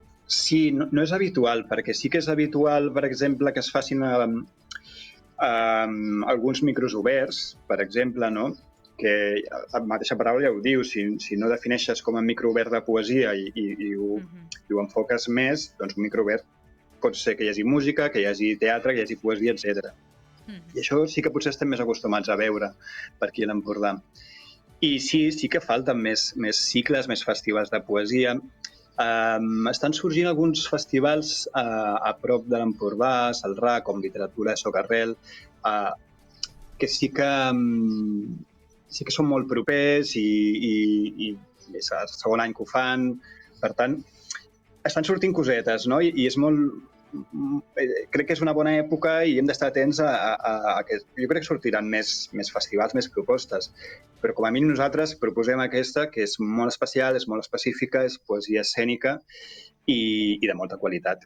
El cicle de poesia escènica “El Pati de Can Gil” torna aquest estiu amb quatre espectacles que es faran a Corçà i la Bisbal d’Empordà. En una entrevista al Supermatí